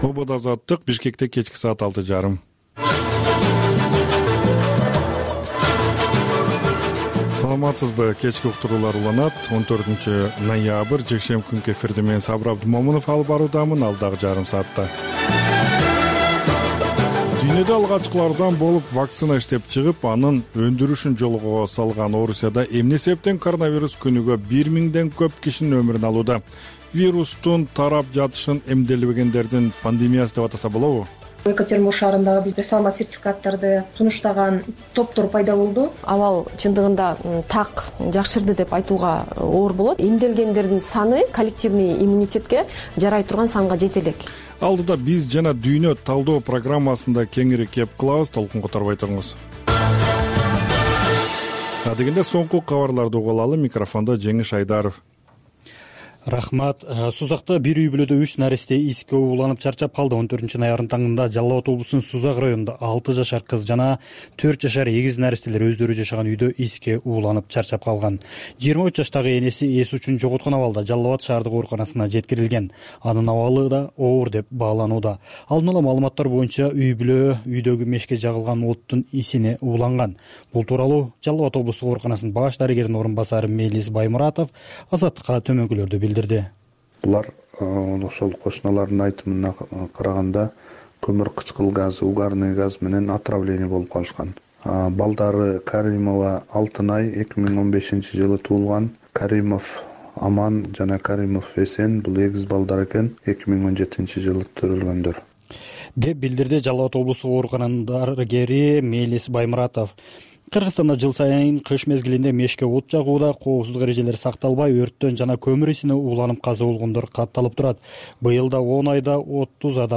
Бул үналгы берүү ар күнү Бишкек убакыты боюнча саат 18:30ден 19:00га чейин обого түз чыгат.